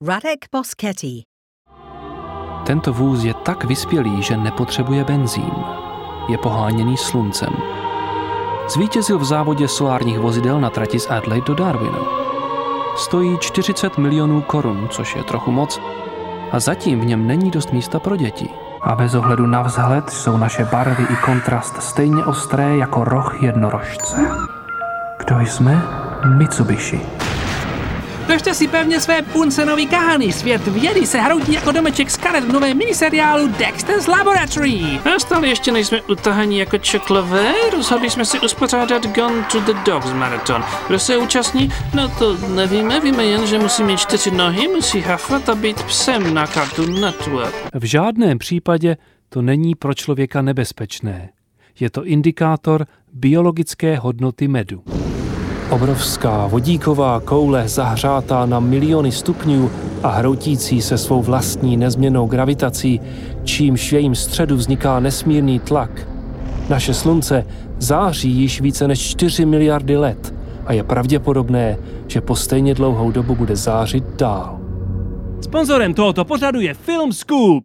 Czech voiceover artist